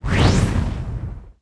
attack_act_2.wav